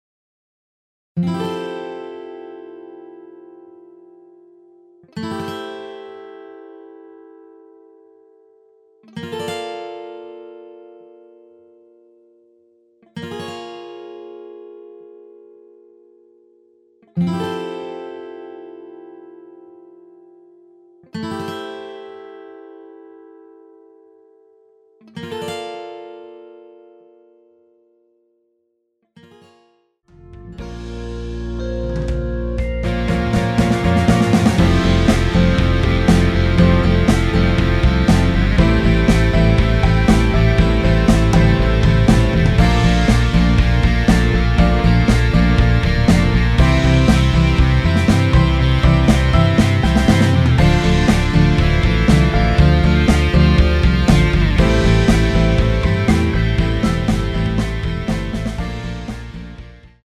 대부분의 여성분이 부르실수 있는 키로 제작 되었습니다.
앞부분30초, 뒷부분30초씩 편집해서 올려 드리고 있습니다.
중간에 음이 끈어지고 다시 나오는 이유는